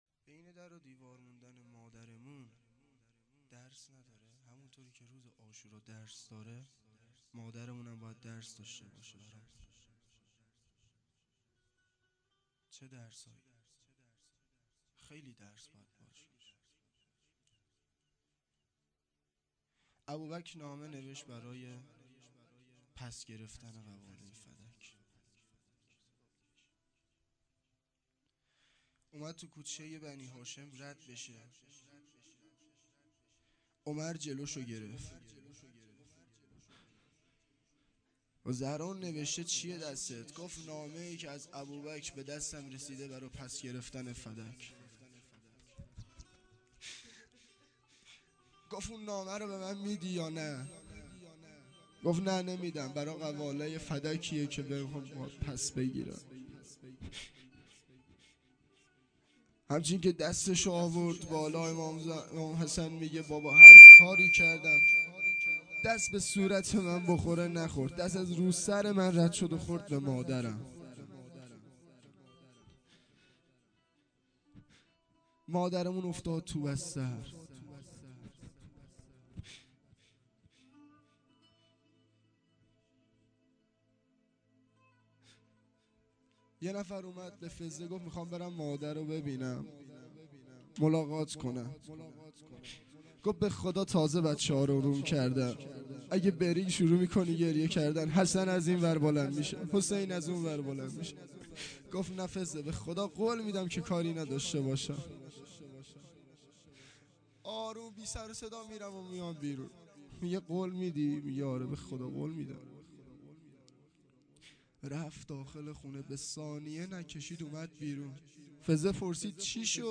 روضه
فاطمیه دوم شب اول